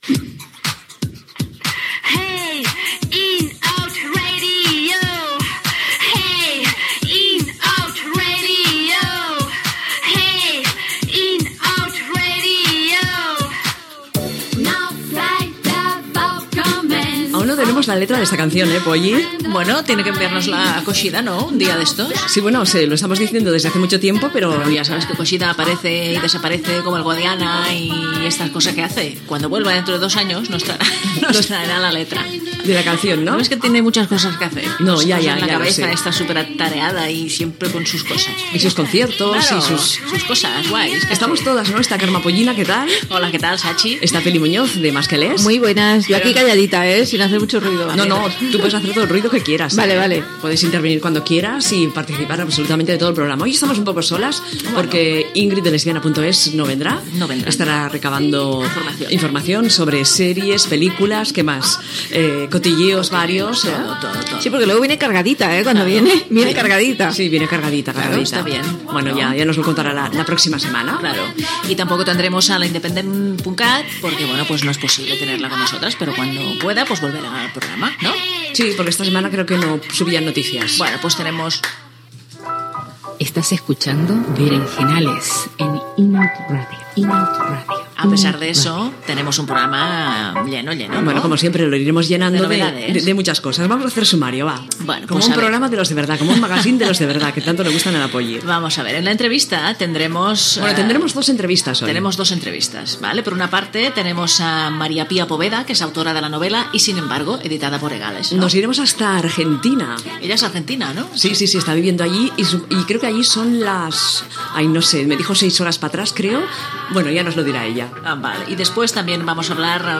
Indicatiu de la ràdio, presentació del programa, indicatiu del programa, sumari de continguts, el Festival Primavera Sound, tema musical
Divulgació